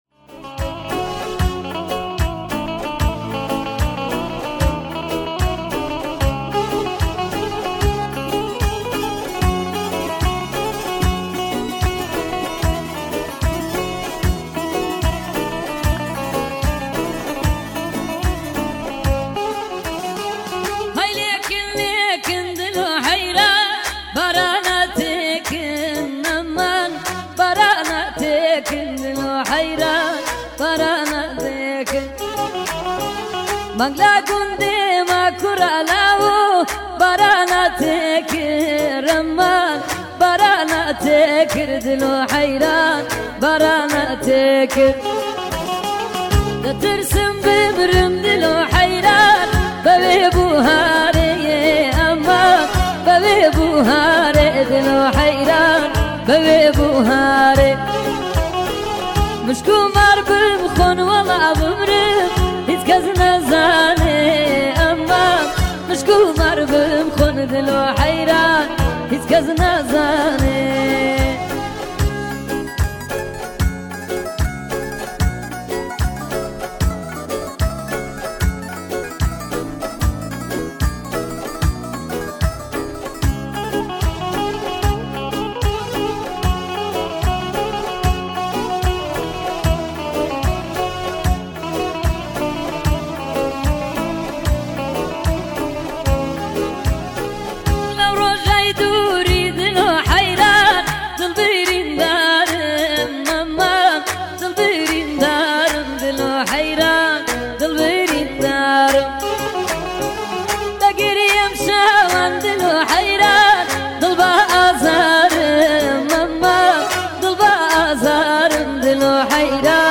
آهنگ کردی غمگین